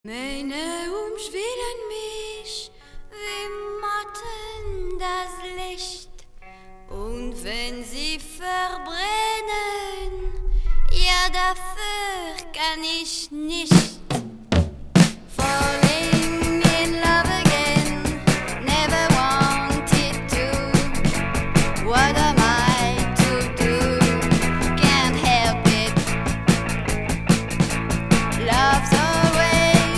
Rock new wave